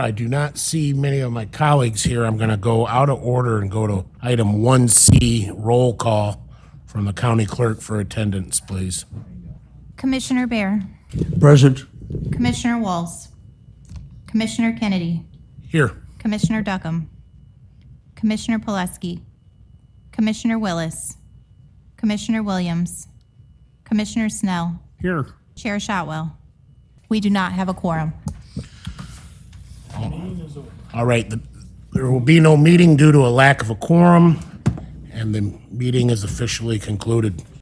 The audio below is from the publicly available recording of Tuesday’s meeting, which was led by Commissioner Corey Kennedy with Board Chair Steve Shotwell not in attendance.